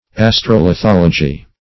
Search Result for " astrolithology" : The Collaborative International Dictionary of English v.0.48: Astrolithology \As`tro*li*thol"o*gy\, n. [Astro- + lithology.]
astrolithology.mp3